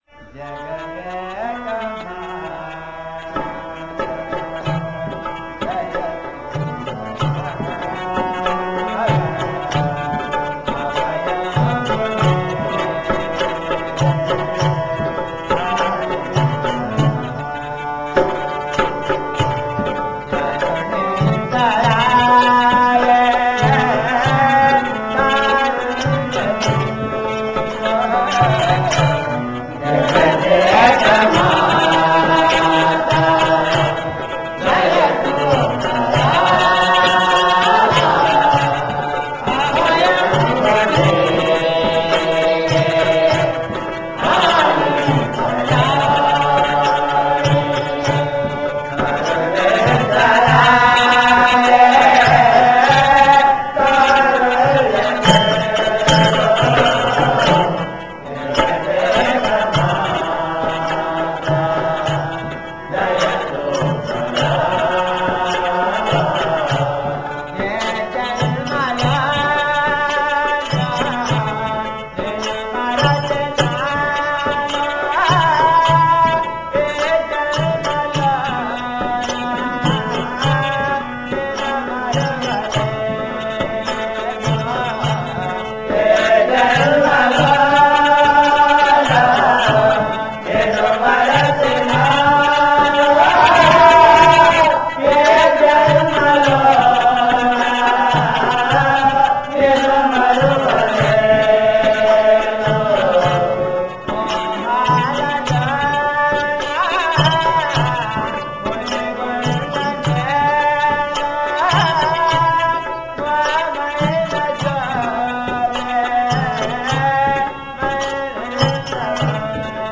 Recorded at the Adivarapupeta ashram in 1996
Each night at the Adivarapupeta ashram, especially on Fridays, devotees sing bhajans and experience the transforming spiritual presence of Shivabalayogi.